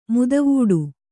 ♪ mudavūḍu